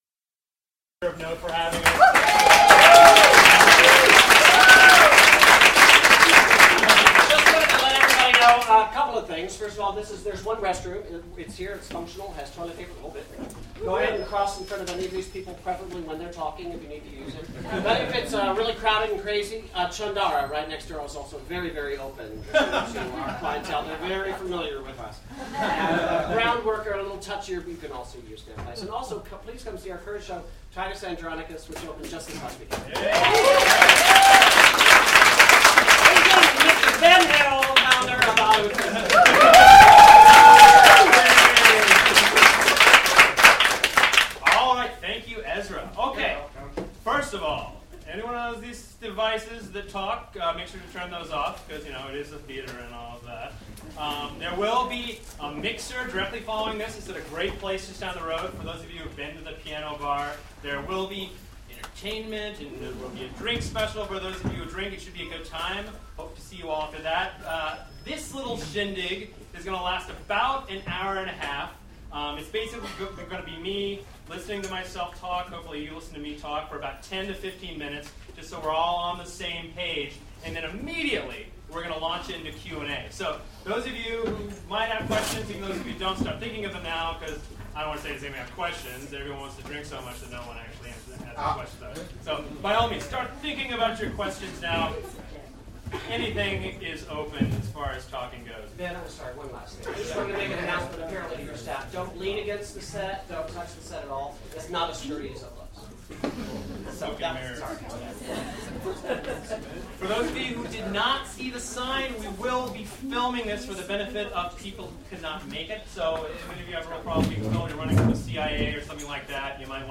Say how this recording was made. For those of you that couldn’t make the Town Hall, we recorded an audio track of the proceedings. feb_2010_town_hall_audio.mp3